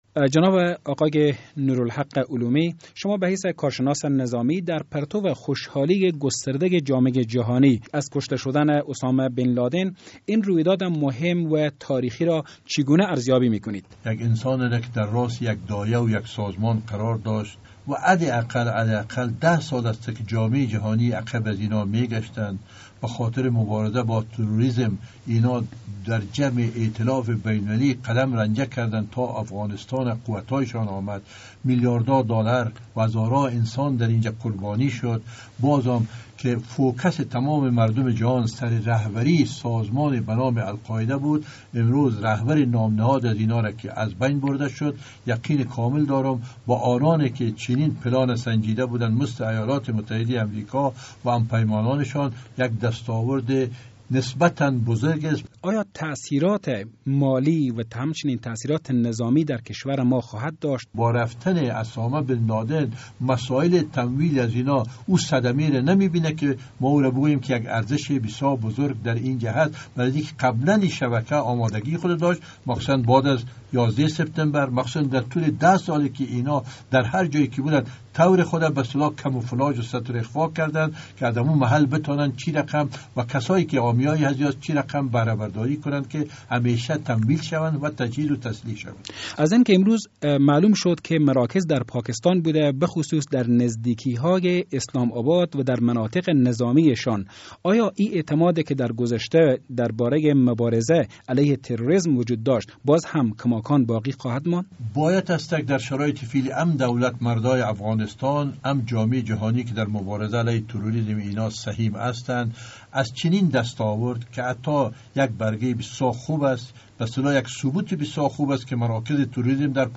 مصاحبه با نور الحق علومی در مورد کشته شدن اسامه بن لادن